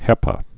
(hĕpə)